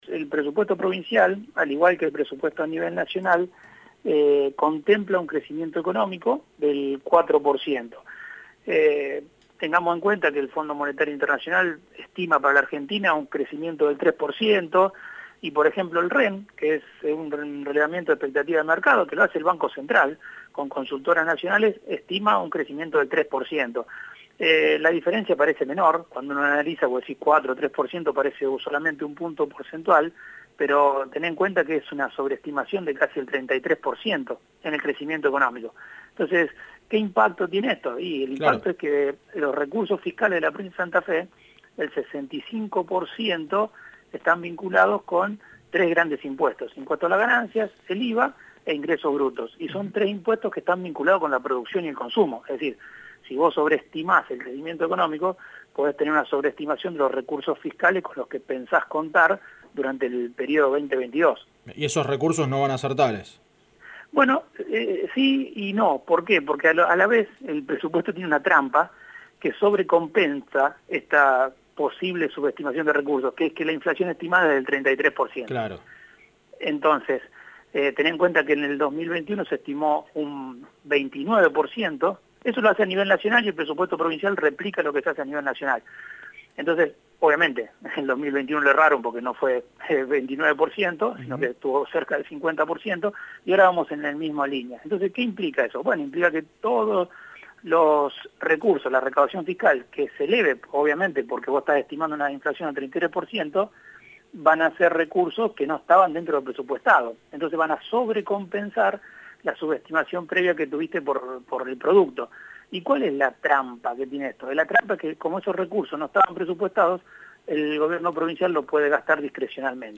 en diálogo con El Puente (Radio Mitre Rosario)